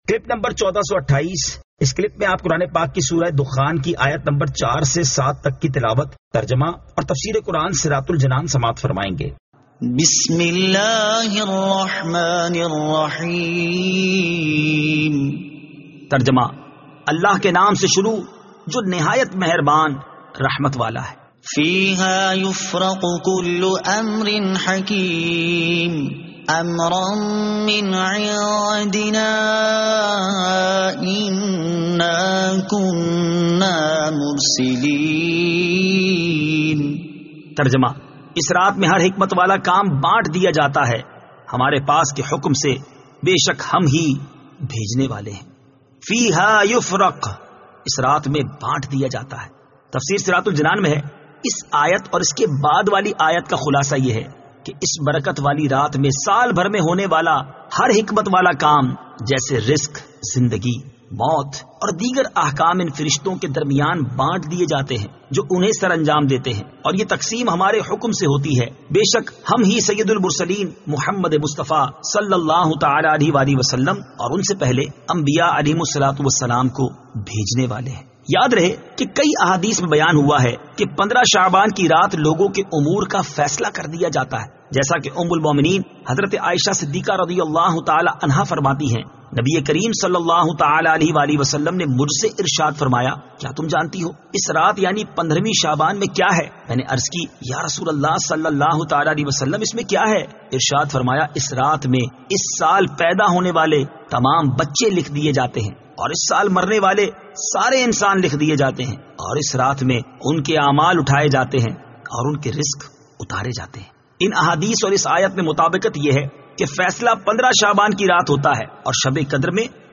Surah Ad-Dukhan 04 To 07 Tilawat , Tarjama , Tafseer